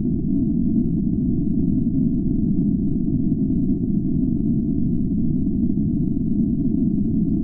Examples_Audio_UnderwaterWhiteNoise.wav